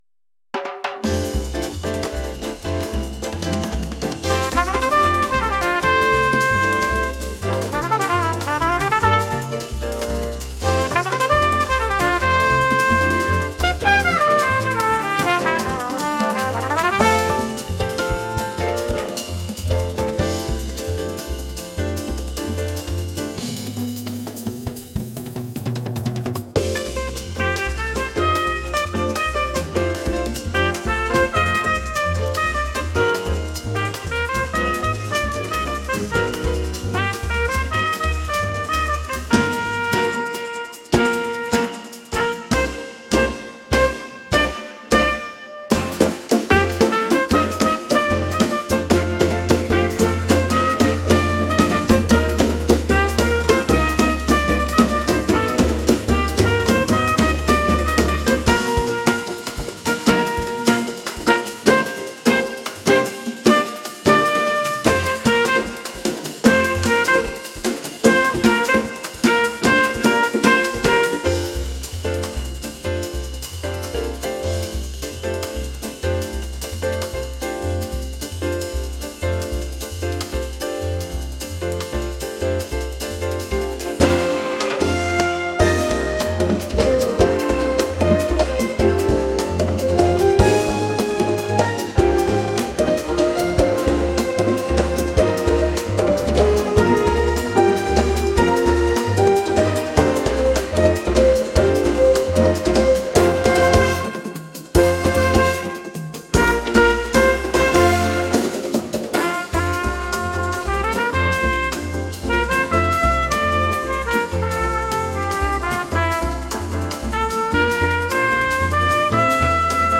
energetic | latin | jazz